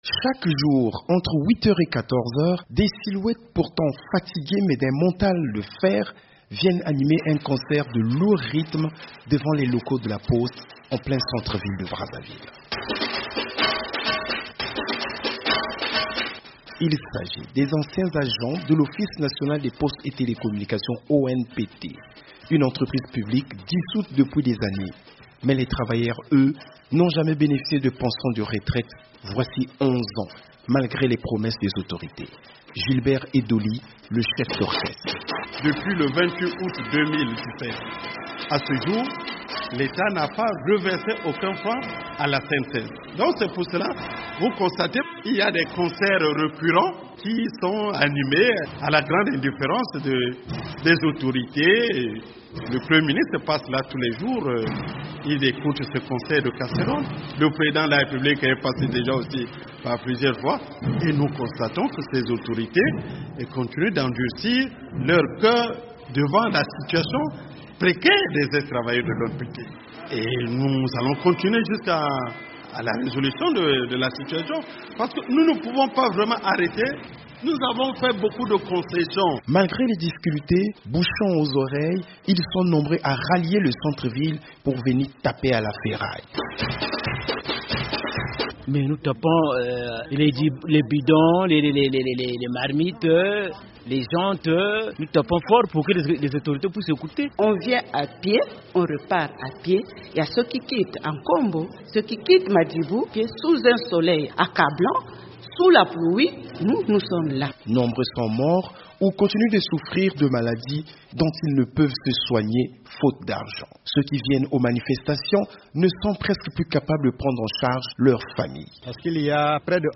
Chaque jour, entre 8 et 14 heures, des silhouettes visiblement fatiguées, mais affichant un mental de fer, animent un concert bruyant devant les locaux de la poste, en plein centre-ville.
A Brazzaville, 1200 anciens travailleurs mécontents organisent chaque jour un concert de casseroles
"On tape sur les bidons, les jantes de véhicules, les marmites. On tape aussi fort pour que les autorités écoutent", renchérit un autre manifestant qui a préféré rester anonyme.